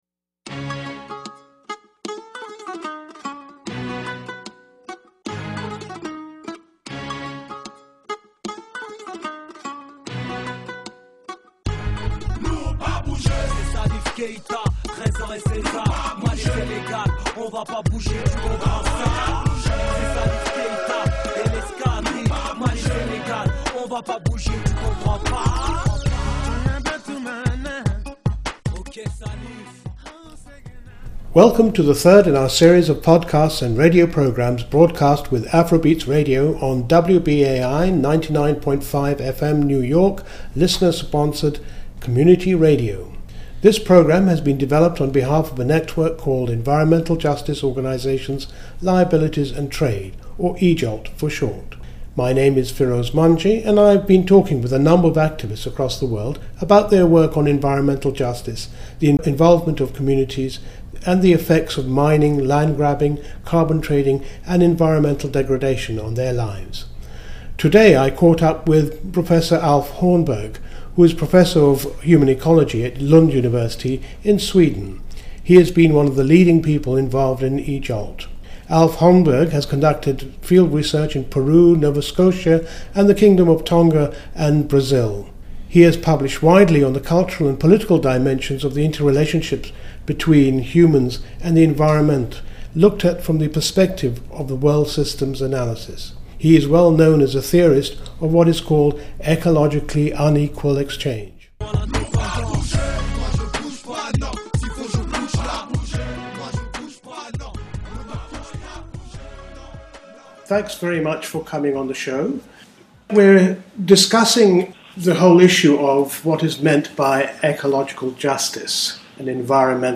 These are the first 15 minutes of their fascinating talk.